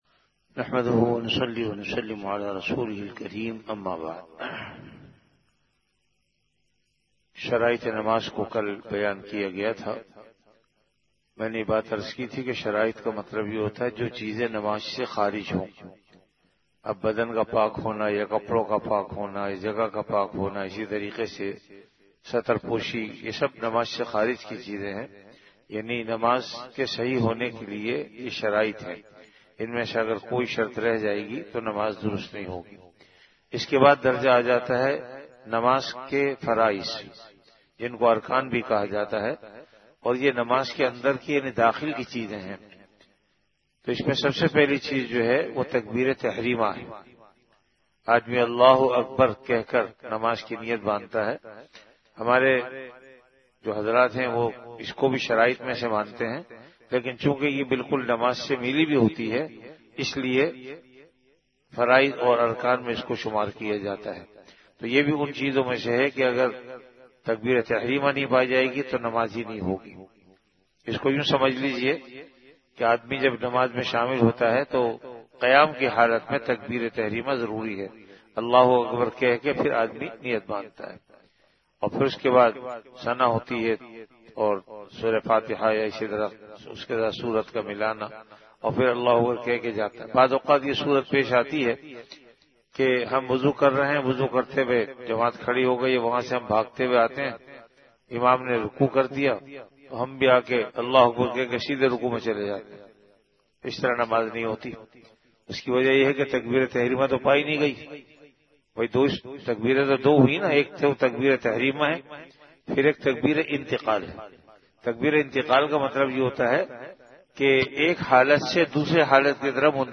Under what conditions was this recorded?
Ramadan - Dars-e-Hadees · Jamia Masjid Bait-ul-Mukkaram, Karachi